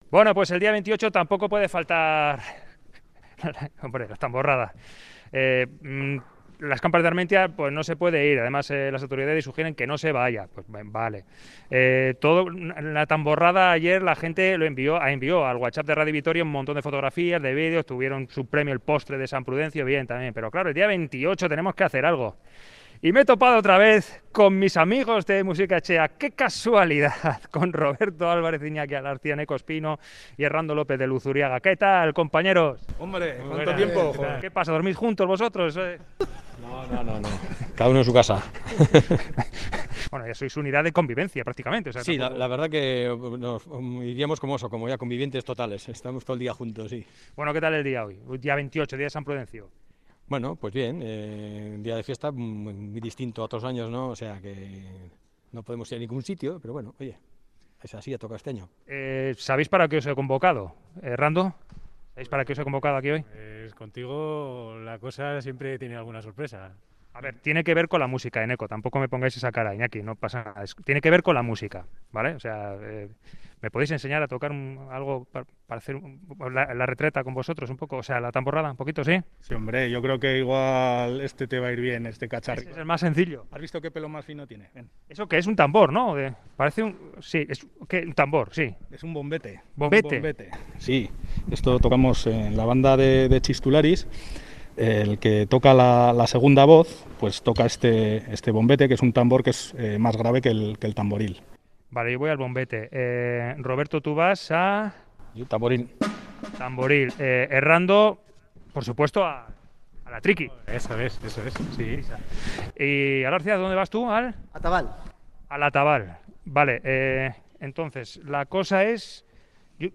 Aprendemos a tocar una tamborrada en condiciones con los profesores de Musiketxea
Audio: El día de San Prudencio no nos resistimos a tocar la tamborrada.